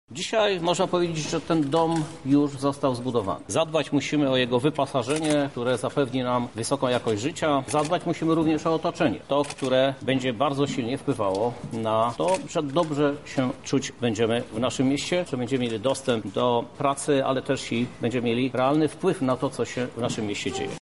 Krzysztof Żuk podkreślił, że już od 8 lat jego ekipa zmienia Lublin i chce kontynuować to dzieło: